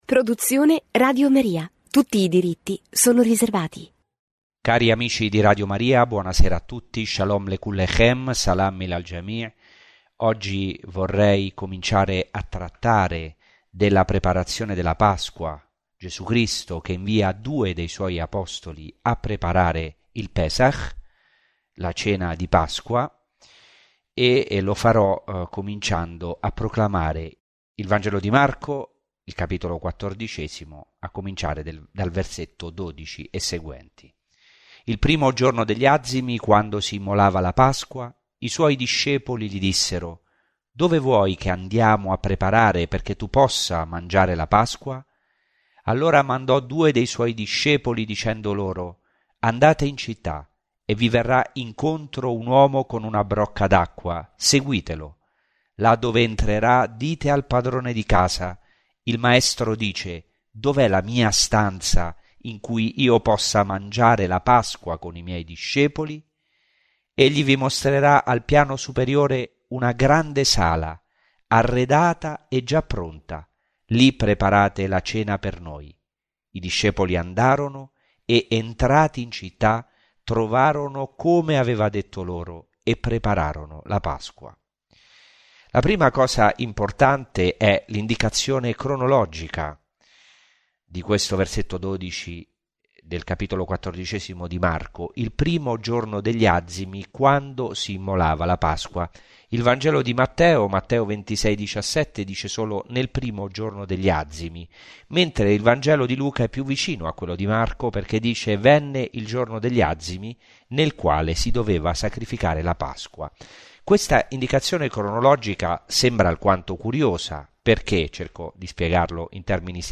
Ciclo di catechesi